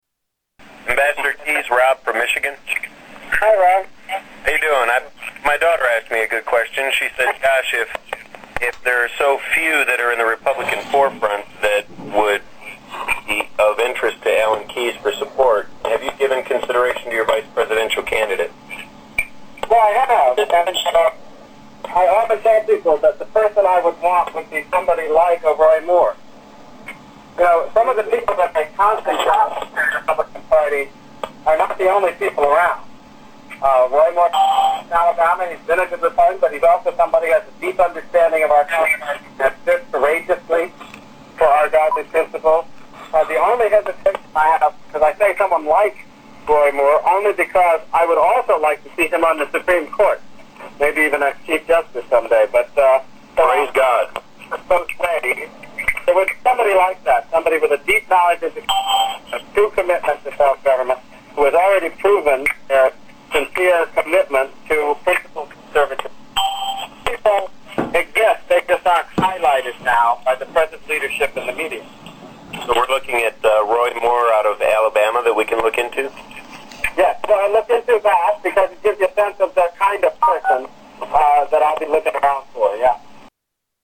Tags: Political Alan Keyes audio Alan Keyes Alan Keyes Speeches The Tea Part